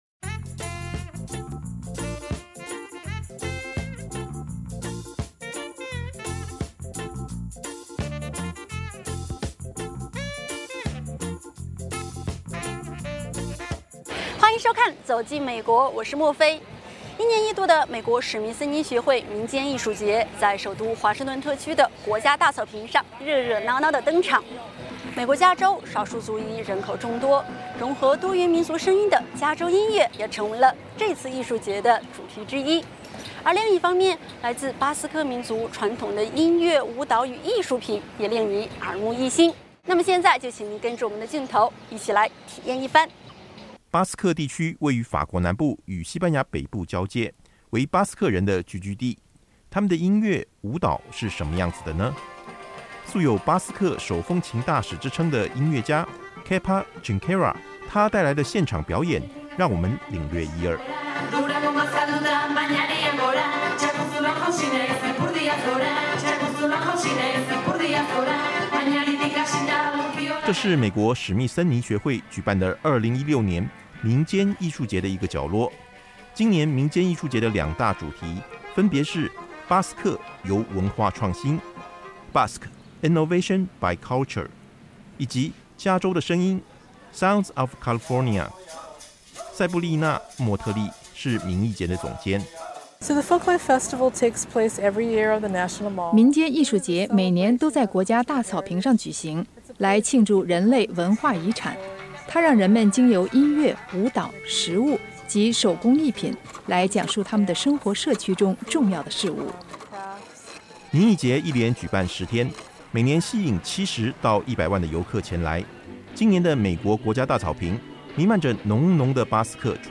一年一度的美国史密森尼学会民间艺术节，在首都华盛顿特区的国家大草坪上热热闹闹地登场。美国加州少数族裔人口众多，融合多元民族声音的加州音乐成为今年艺术节的主题之一。
他们手里舞动的长鞭，发出爆裂般的巨响，用来警告人们赶紧回避，因为现在是群魔逛大街的时刻。
草坪上坐满了聆听音乐的人群，美国民意中枢的国会大厦就矗立在音乐会舞台后方。